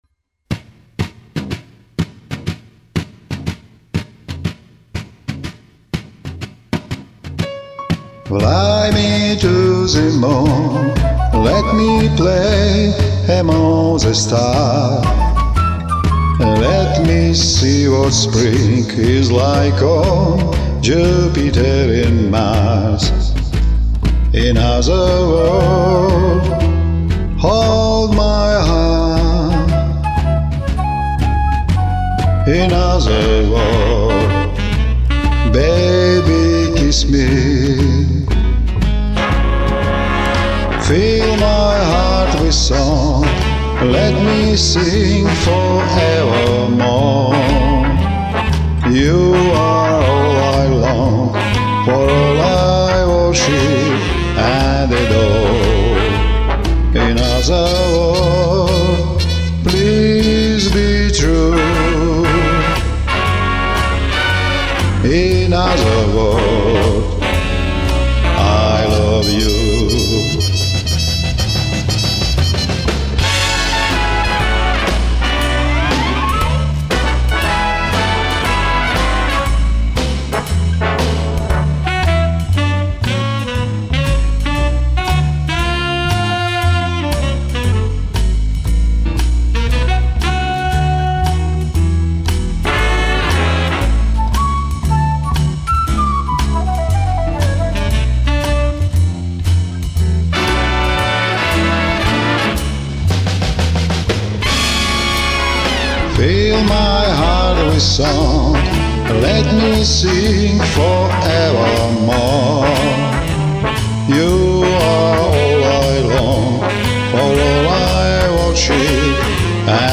Мммм...тембр...бархат!!!